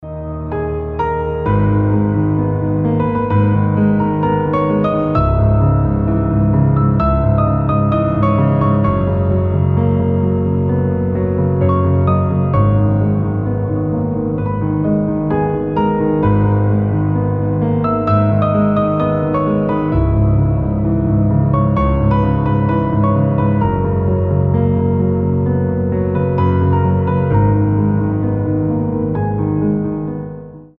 • Качество: 320, Stereo
грустные
спокойные
без слов
красивая мелодия
пианино
нежные
Neoclassical
Красивое произведение на фортепьяно